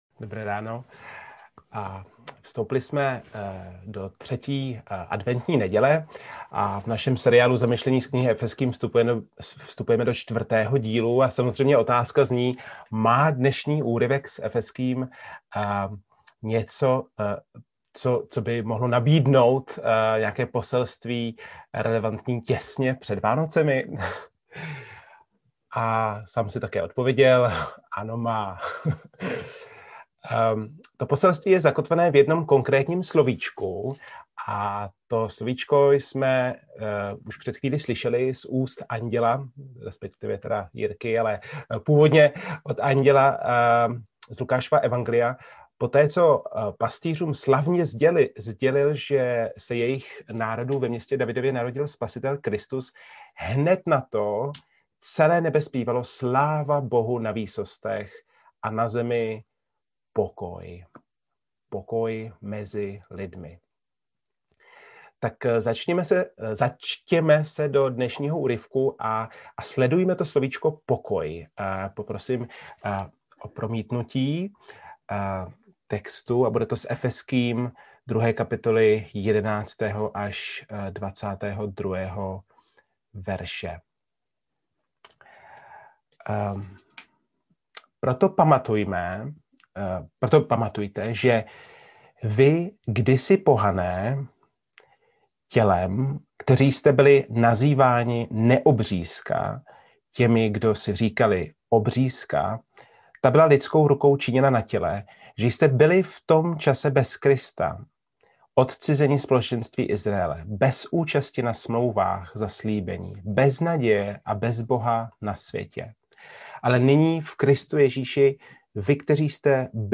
Nedělní kázání 13.12.2020